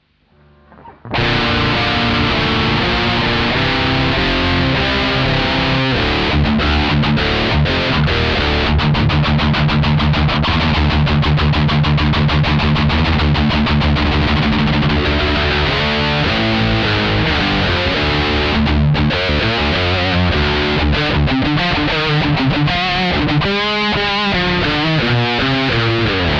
Там в левое ухо идет тон, ну, допустим, 100 герц, а правое - 107, разница в семь герц это где-то альфа волны.